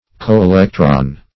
Coelectron \Co`e*lec"tron\, n.